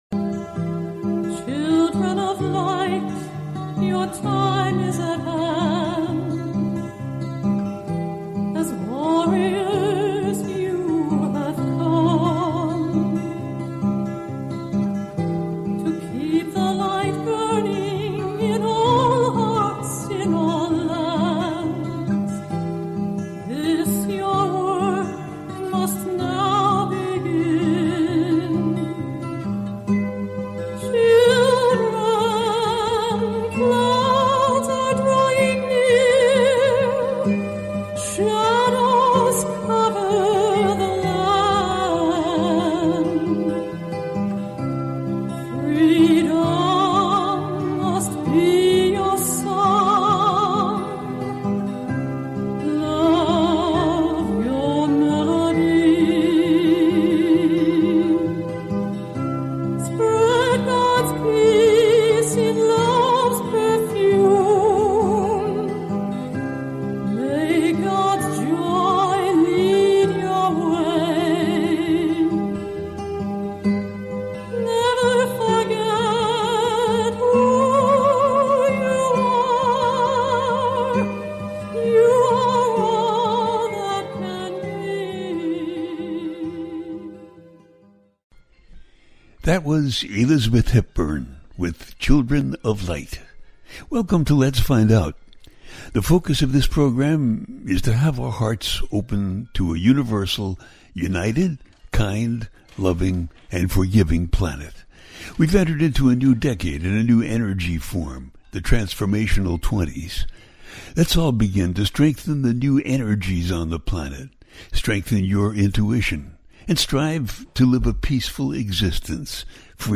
The Pluto-Mars Oppositions - A teaching show